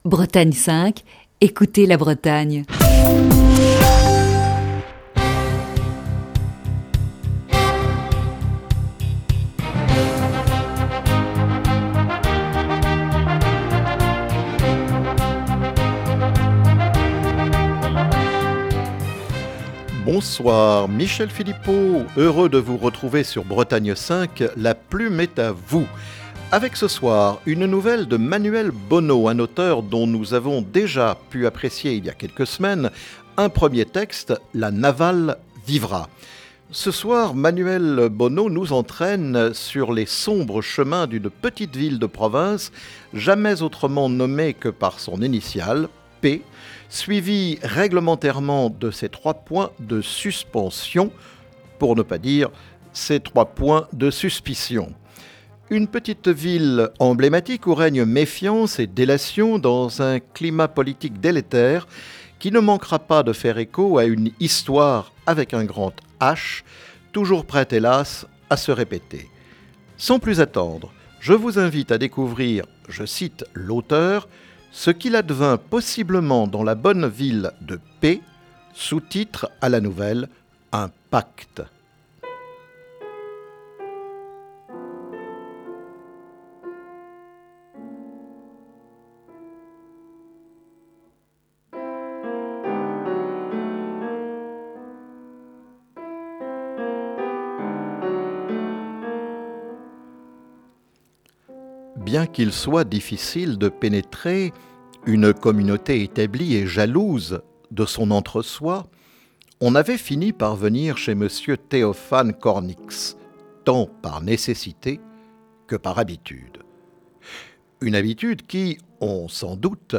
la lecture d'une nouvelle